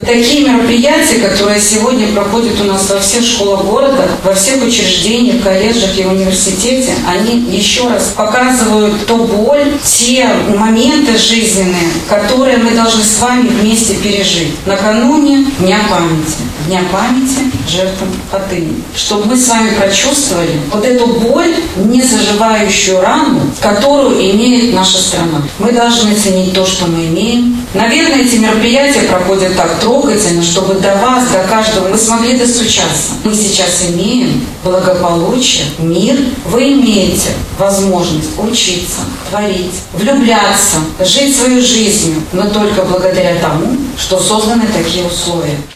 Здесь состоялось городское мероприятие, посвященное памяти Хатынской трагедии.